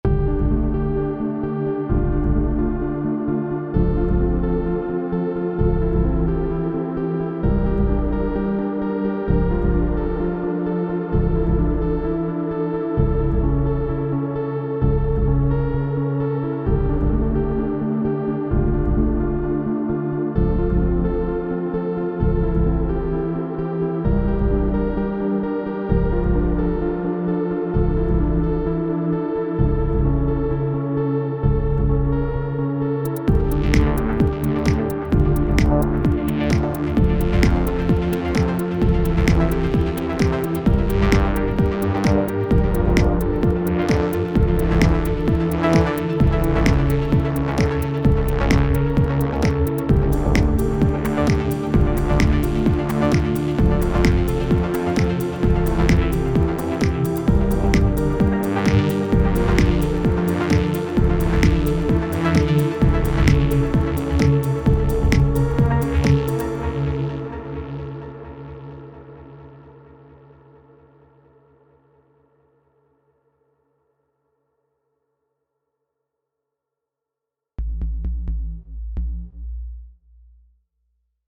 Kategorie: Stückssongs und Sounds